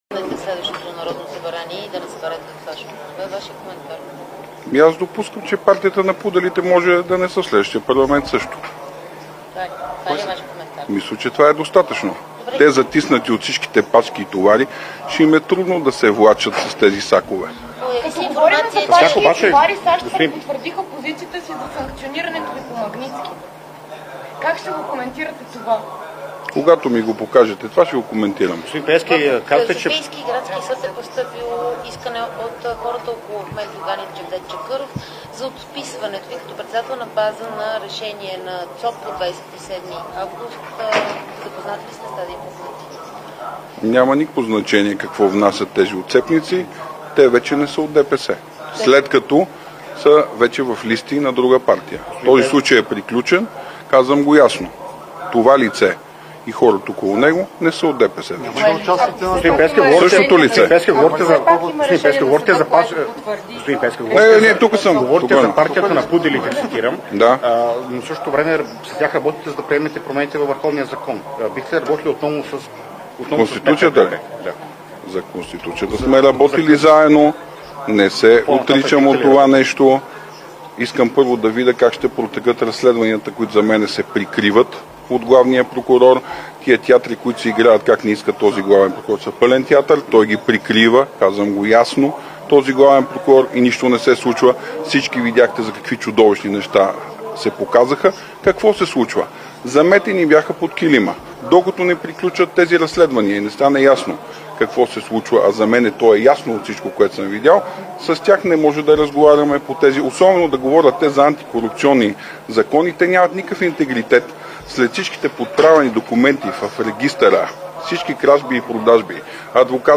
- директно от мястото на събитието (БТА)